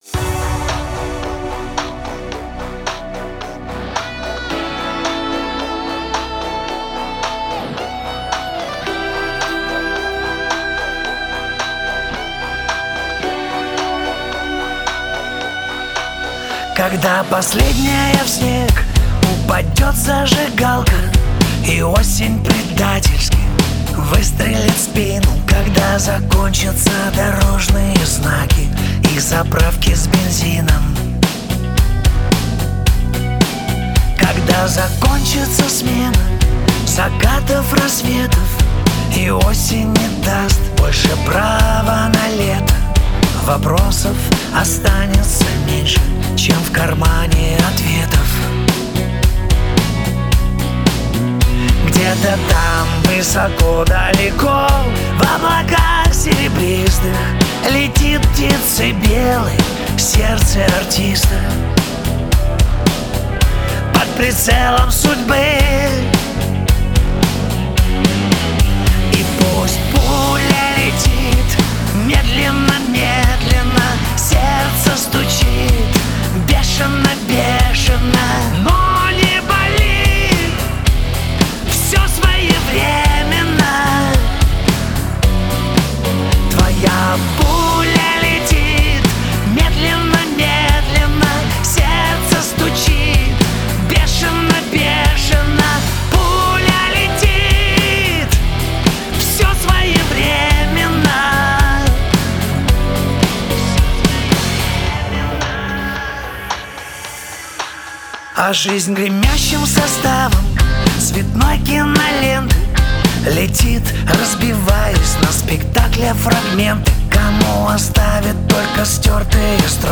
Русский Рок
Жанр: Rock